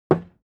HammerHit01.wav